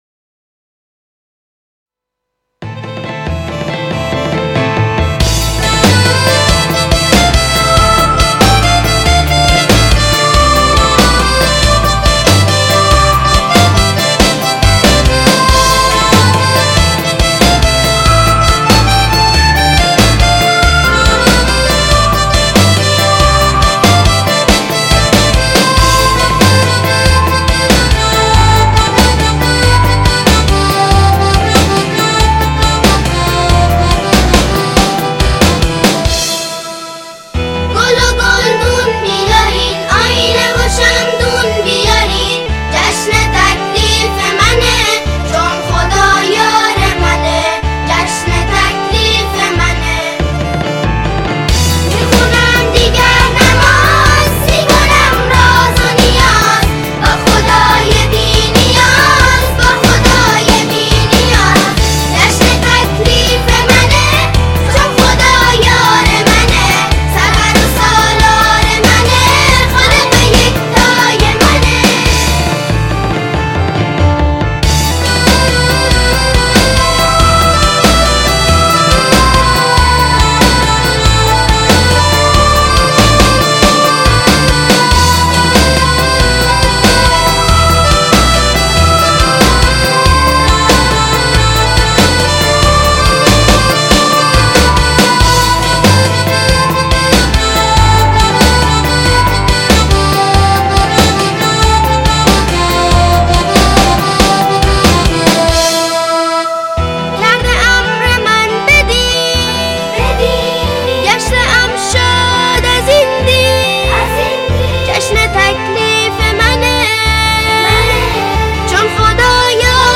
نماهنگ بچگانه